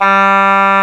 Index of /90_sSampleCDs/Roland L-CDX-03 Disk 1/WND_Bassoons/WND_Bassoon 3
WND A2  DB.wav